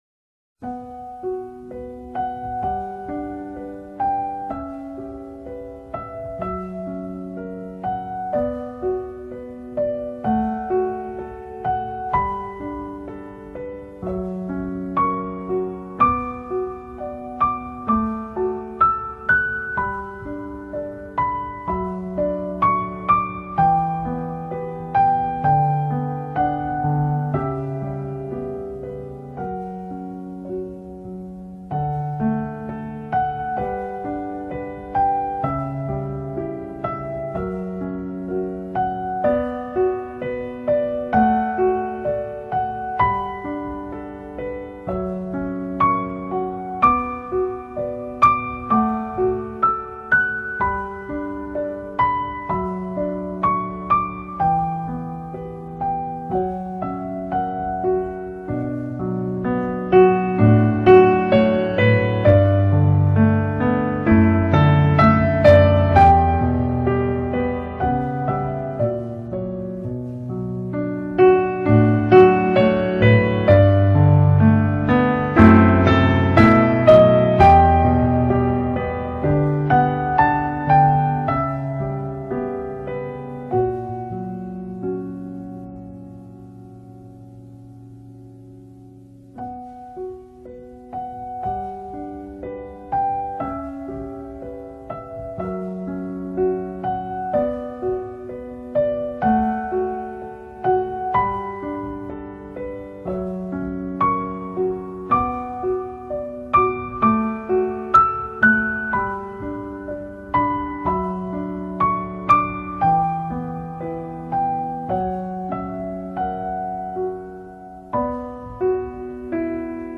описание:   )) такой вот чудесный уголок леса, под музыку капель...
И музыка - волшебная капель!!!;))